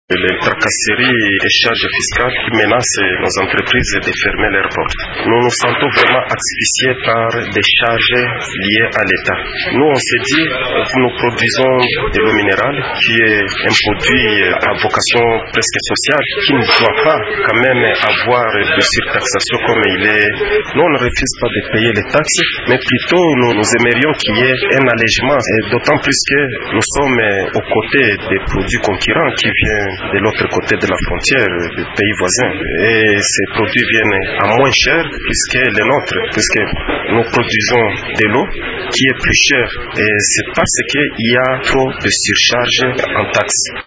Il l’a dit dans ces propos recueillis par Radio Okapi: